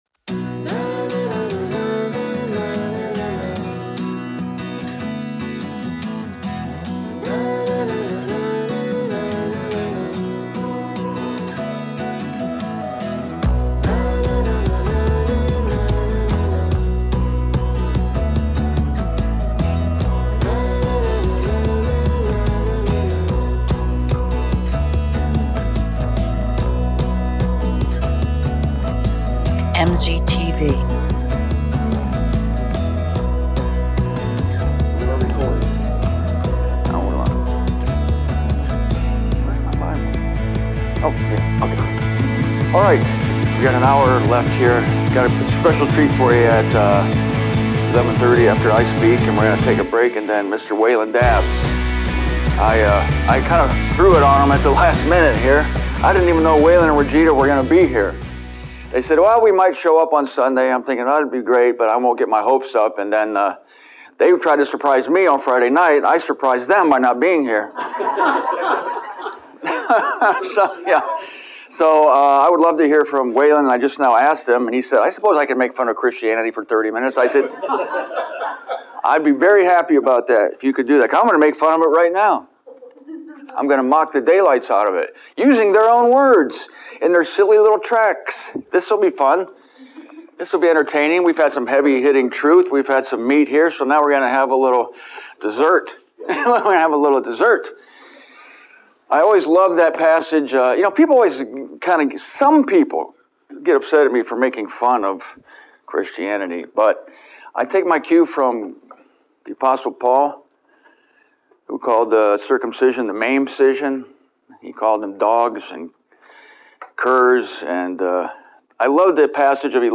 Richmond Conference